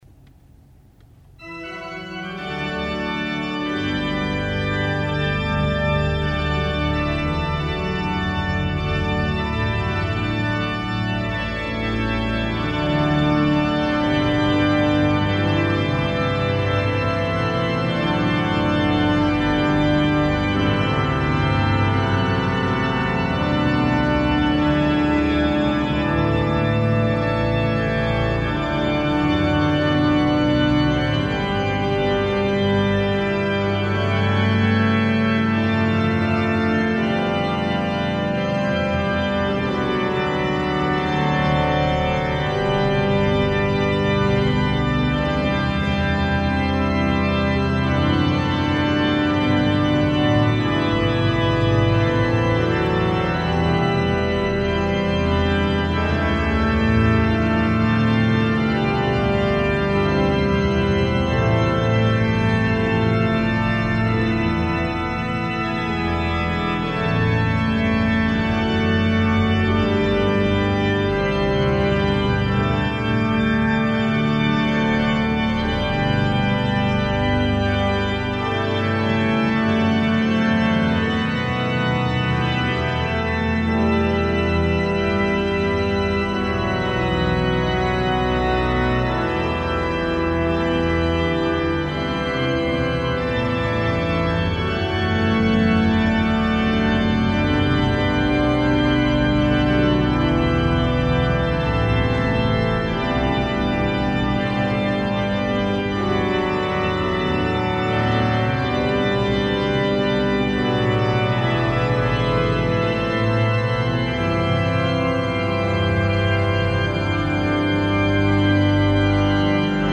Concert d'inauguration du 3 juillet 1994